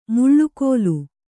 ♪ muḷḷu kōlu